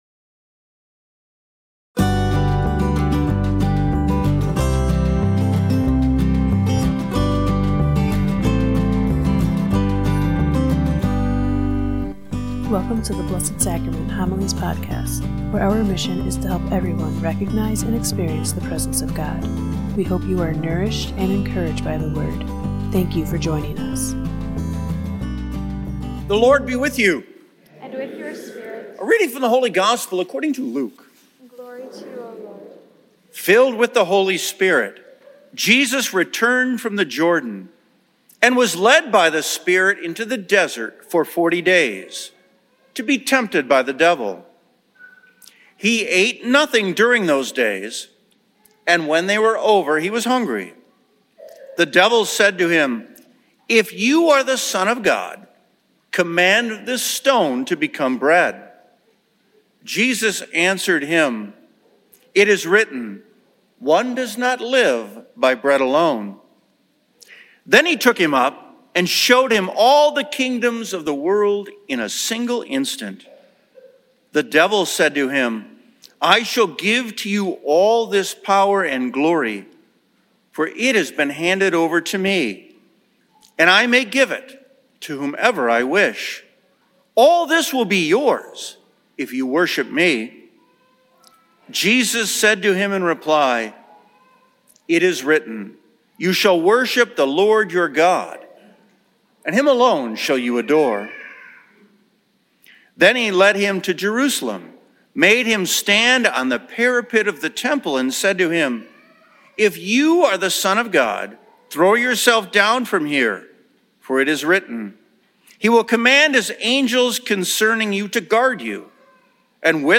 In this thought-provoking homily